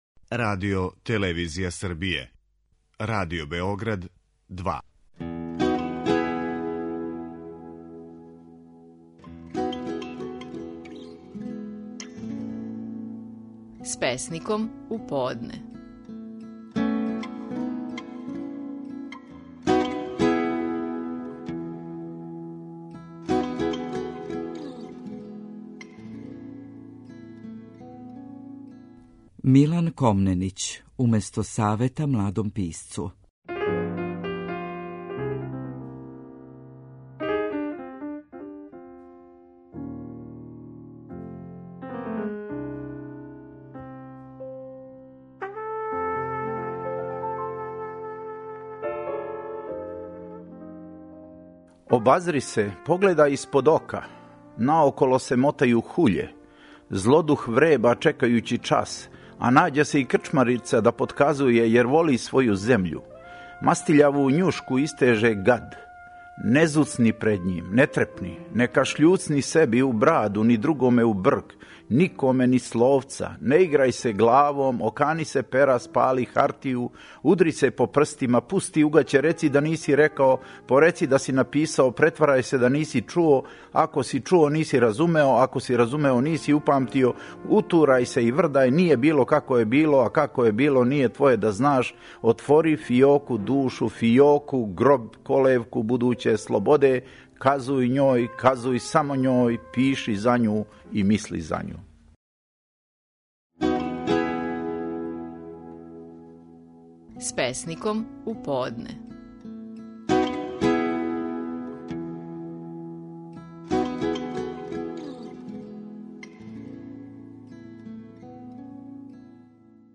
Стихови наших најпознатијих песника, у интерпретацији аутора.
Милан Комненић говори песму: „Уместо савета младом писцу".